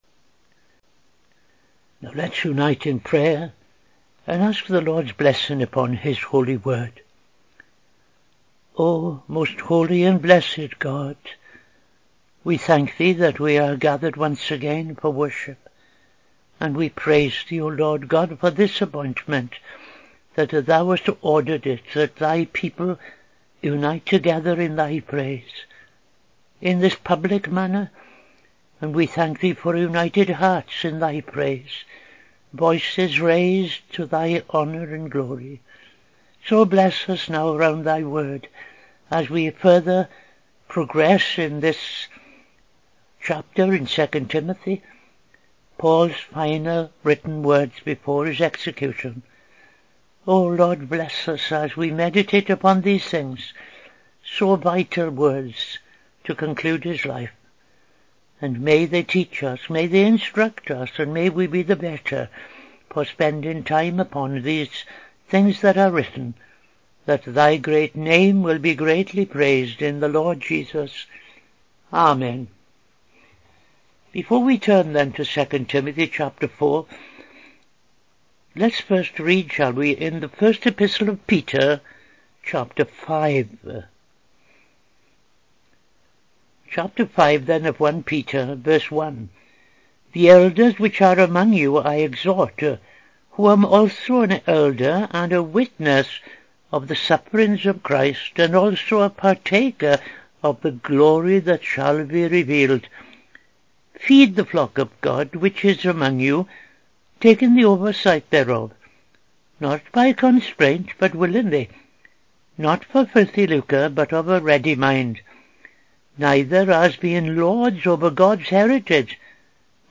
Dedicatory Prayer and Reading I Peter 5:1-11; II Timothy 4:6-8